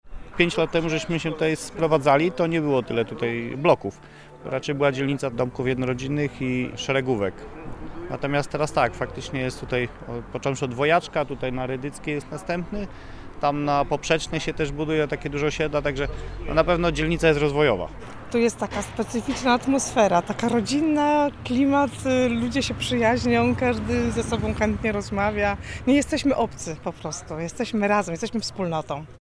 -Pomagamy sobie, jesteśmy razem – mówią mieszkańcy.